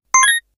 powerUp8.ogg